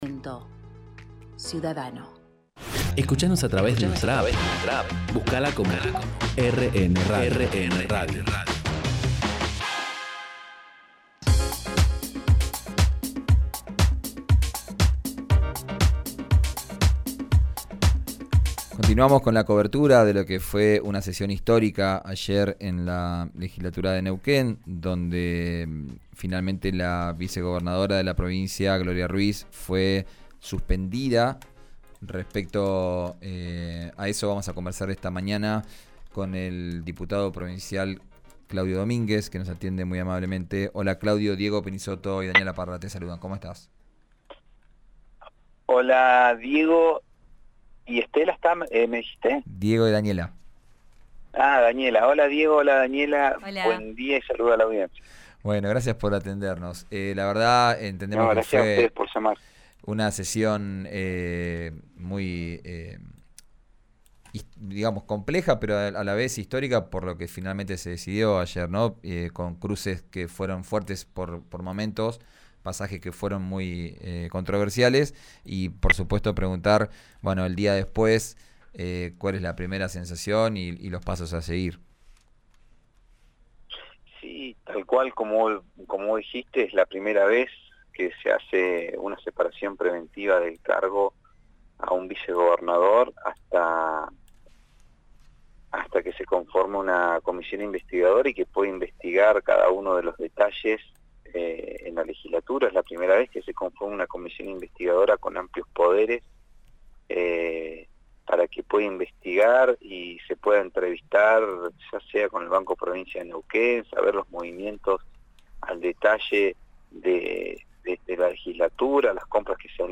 Escuchá a Claudio Domínguez, en RADIO RÍO NEGRO: